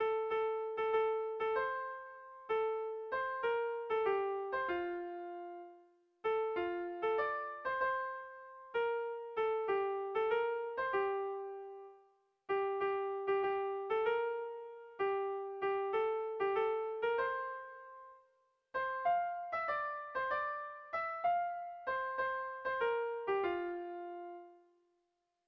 Gabonetakoa
Zortziko txikia (hg) / Lau puntuko txikia (ip)